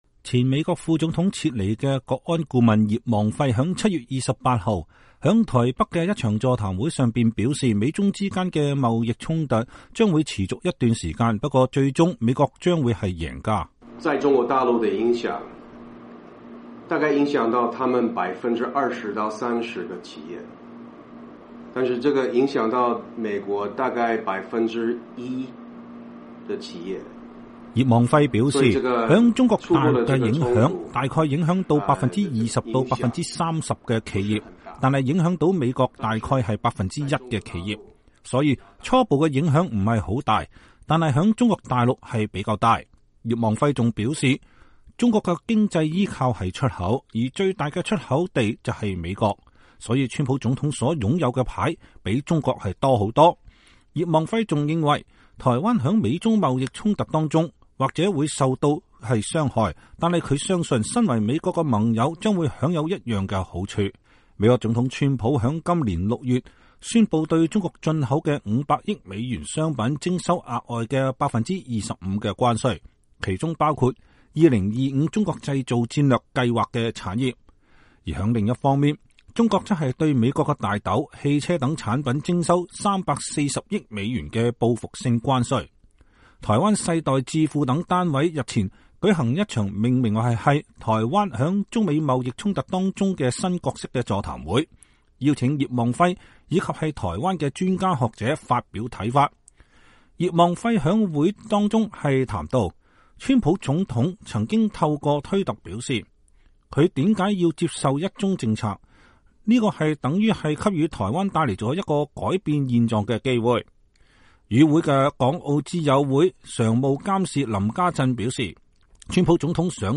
葉望輝日前(28號)在台北的一場座談會上表示，美中之間的貿易衝突，將會持續一段時間，不過最終美國將是贏家。